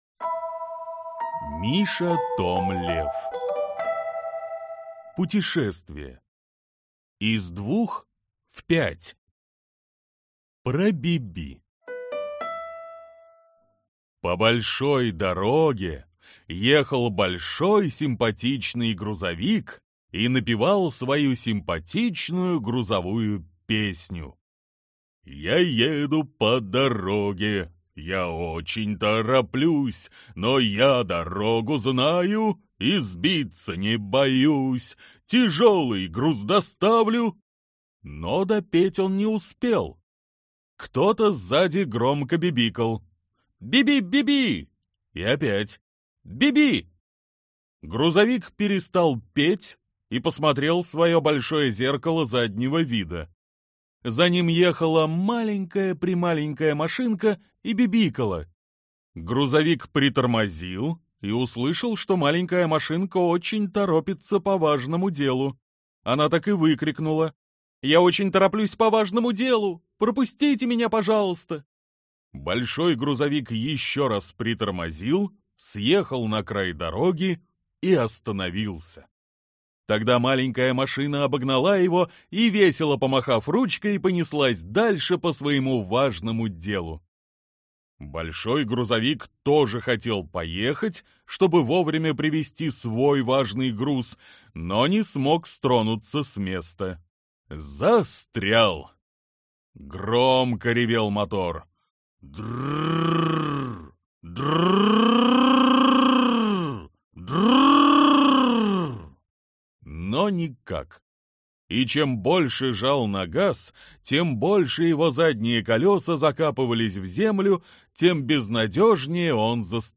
Аудиокнига Путешествие из 2-х в 5-ть | Библиотека аудиокниг